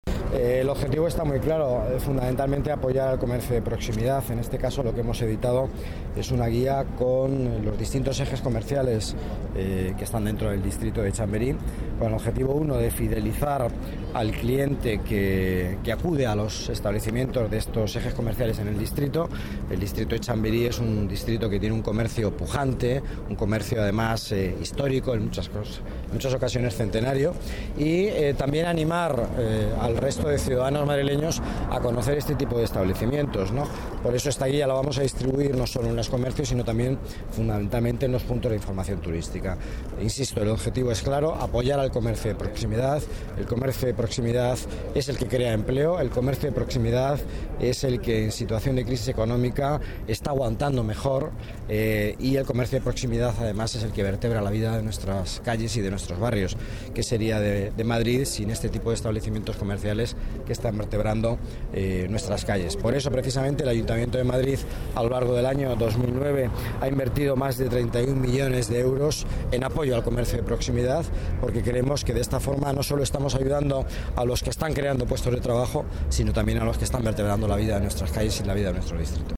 Nueva ventana:Declaraciones del delegado de Economía, Empleo y Participación Ciudadana, Miguel Ángel Villanueva